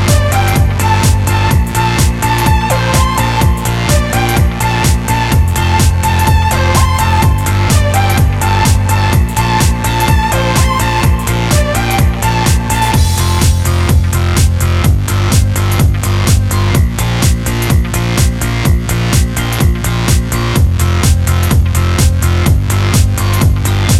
No Backing Vocals Dance 3:01 Buy £1.50